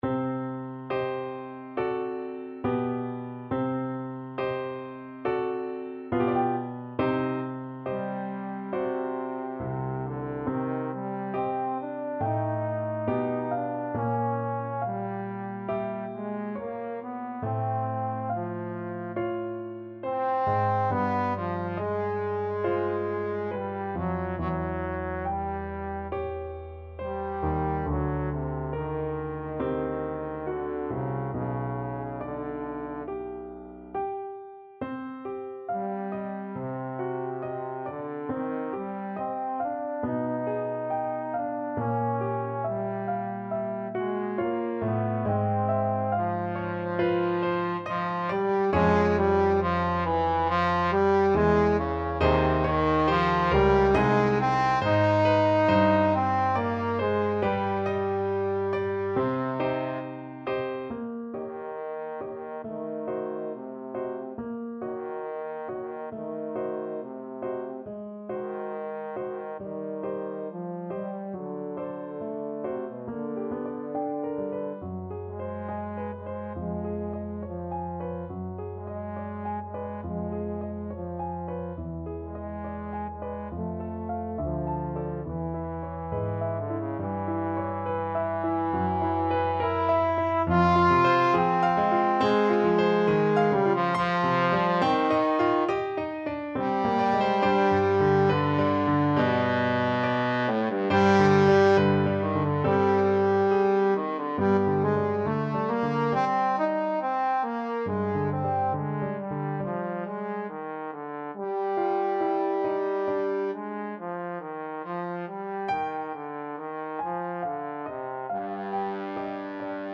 Trombone version
~ = 69 Andante con duolo
Classical (View more Classical Trombone Music)